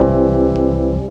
Rhodes_Chord.wav